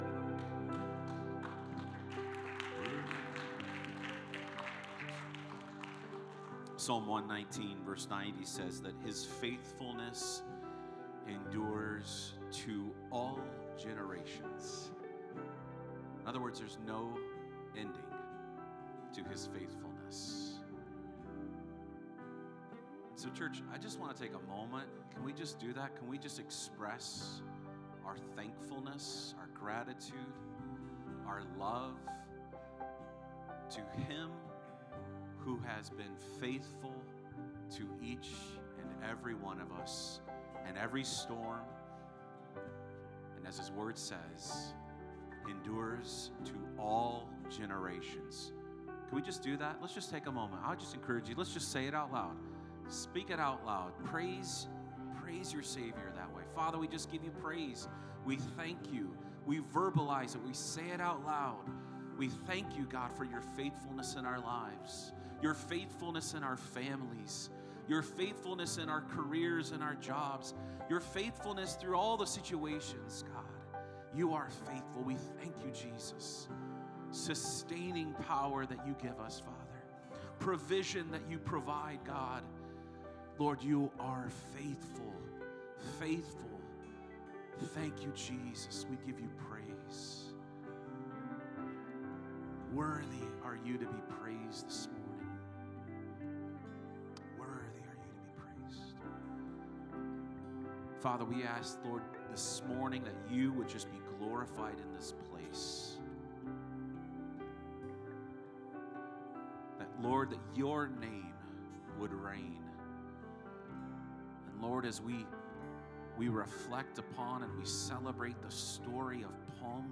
More Messages from Guest Speaker | Download Audio